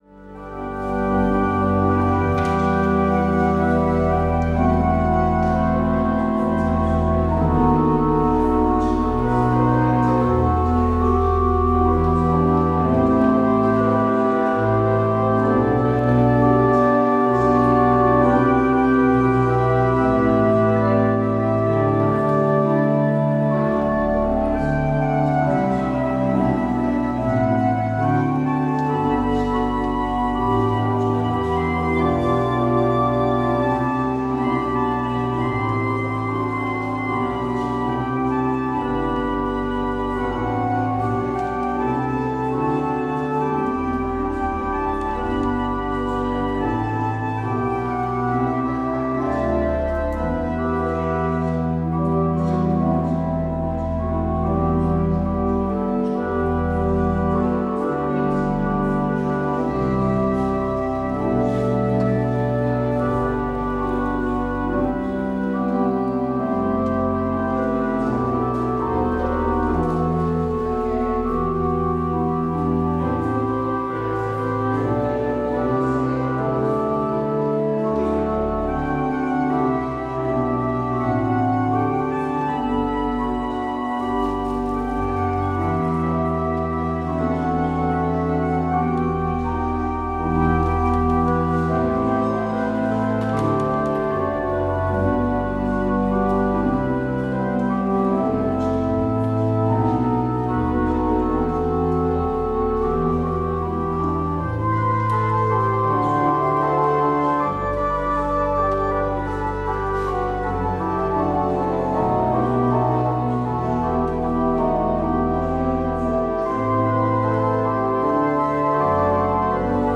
 Beluister deze kerkdienst hier: Alle-Dag-Kerk 21 januari 2026 Alle-Dag-Kerk https